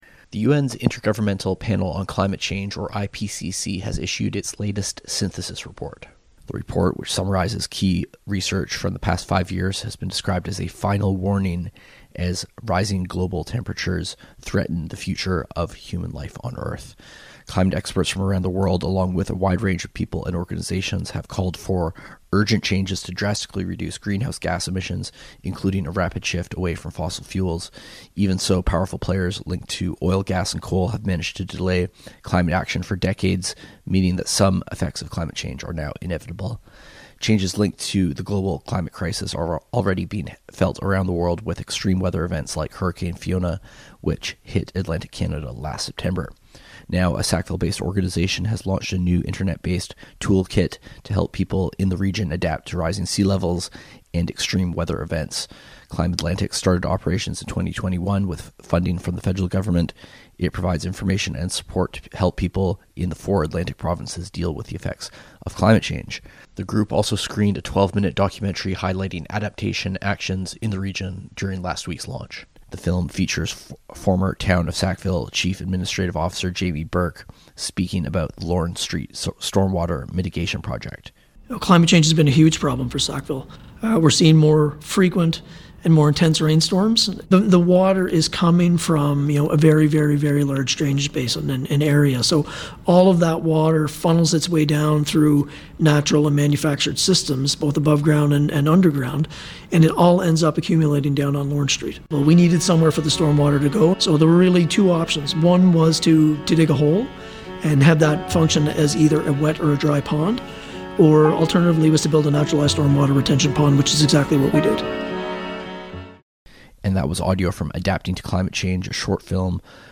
Listen to the audio report from CHMA: